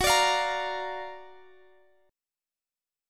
Listen to Gb+M7 strummed